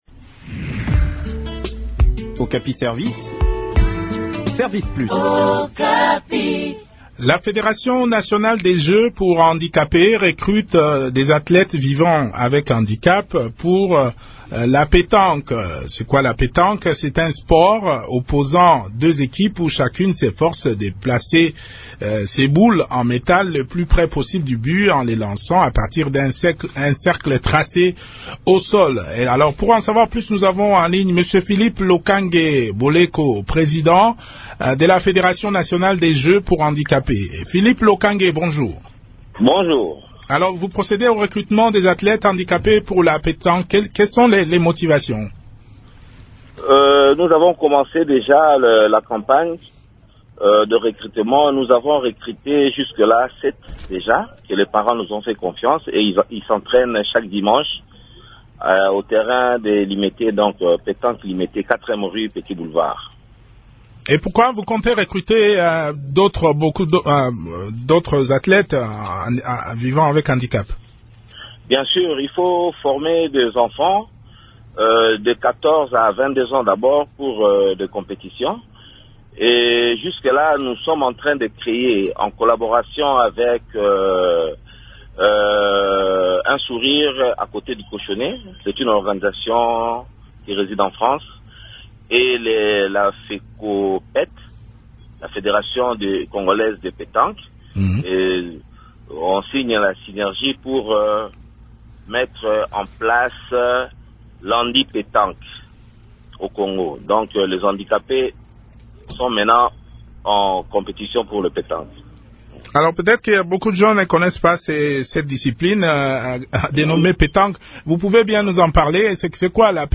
Les réponses dans cet entretien